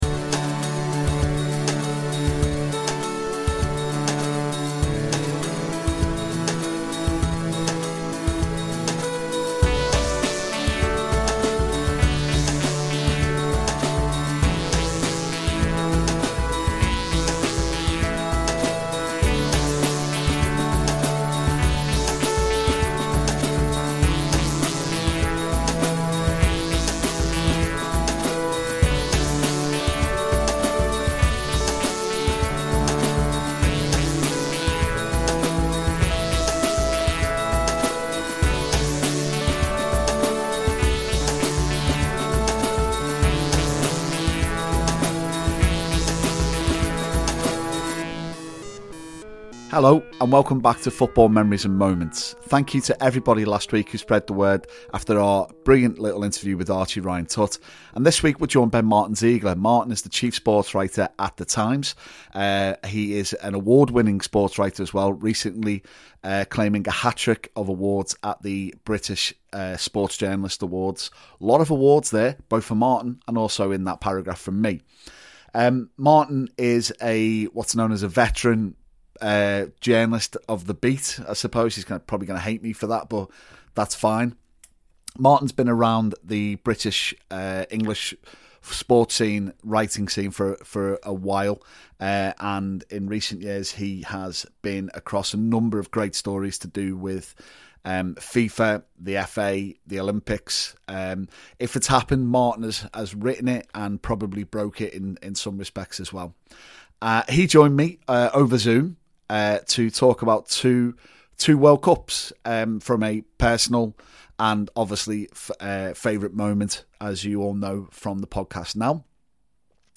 We're joined over Zoom